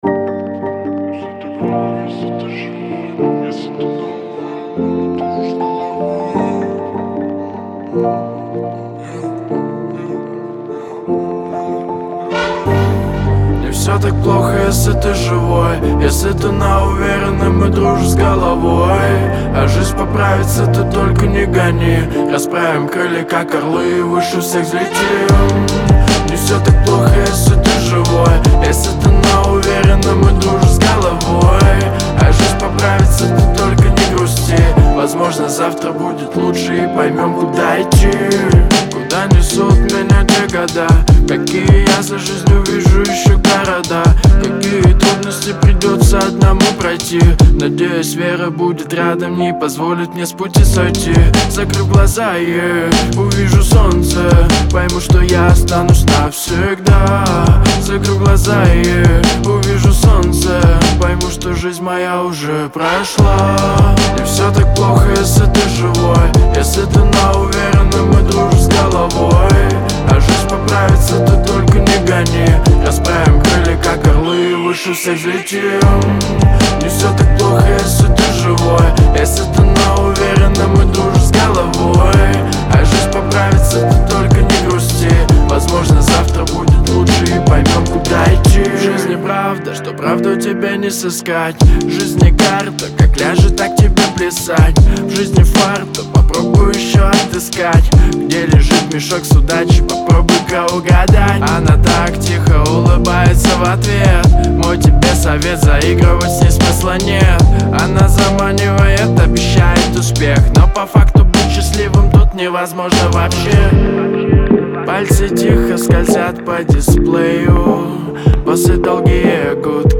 Трек размещён в разделе Русские песни / Фонк.